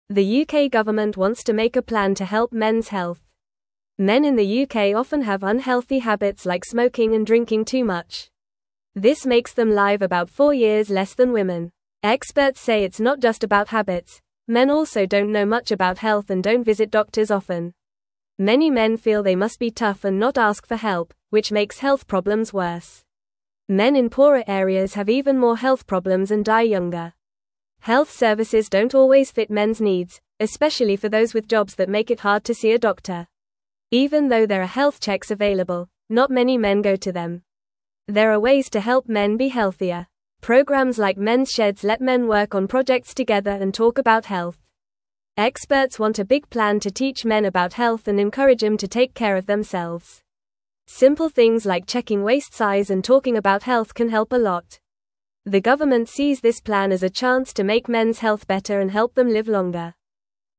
English-Newsroom-Lower-Intermediate-FAST-Reading-Helping-Men-Be-Healthier-in-the-UK.mp3